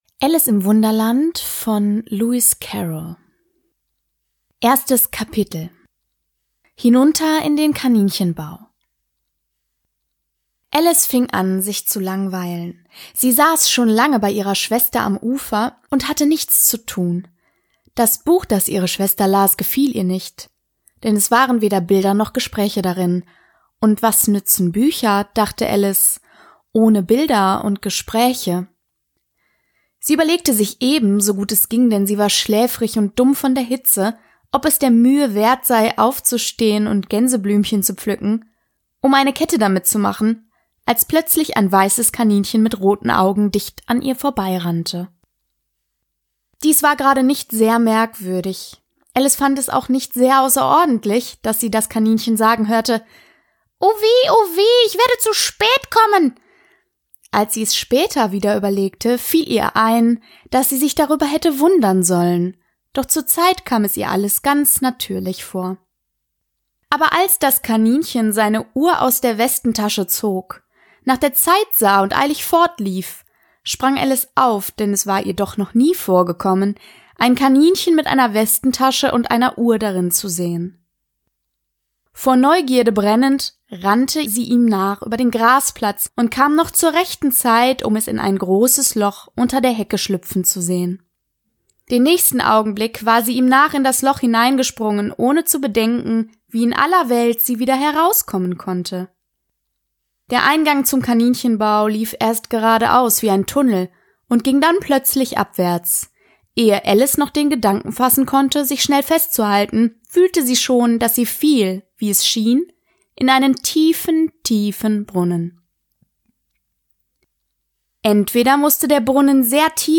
:) Kleine Warnung: Hier werden zumeist gruselige Geschichten gelesen; sie können explizite Gewaltdarstellung und Horrorelemente enthalten.
Jeden letzten Samstag im Monat gibt es dabei eine meist gruselige Geschichte, die ich inzwischen mit Geräuschen, Musik und Ambient-Sounds versehe, um euch ein optimales Hör- und damit Gruselerlebnis zu geben.